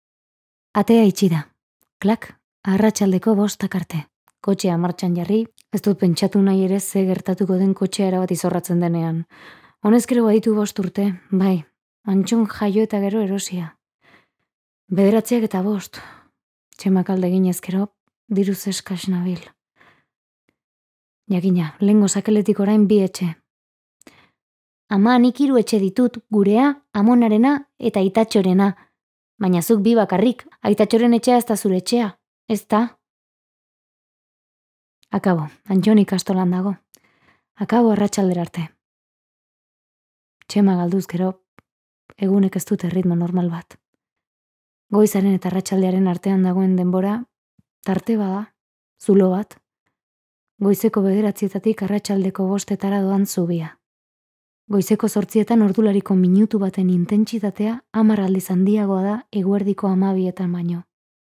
Euskal idazleen audioliburuak paratu ditu sarean eitb-k